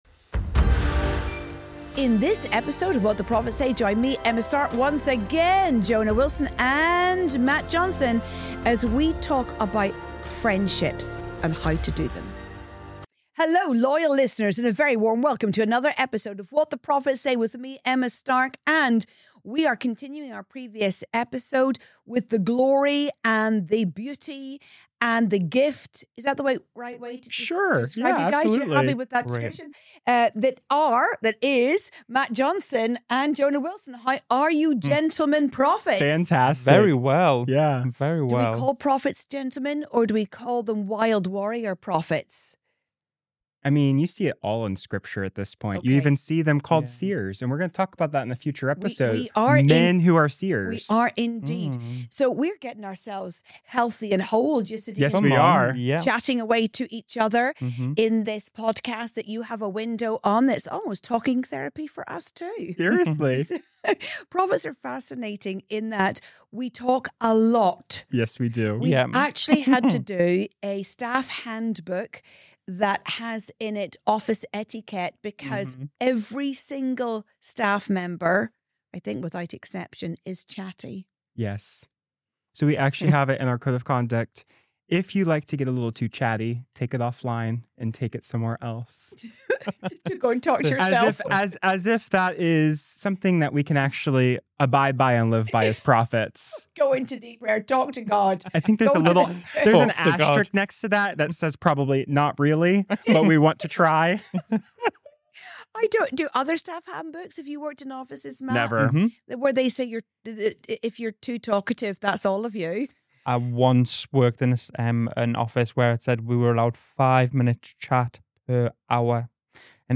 In this honest follow-on conversation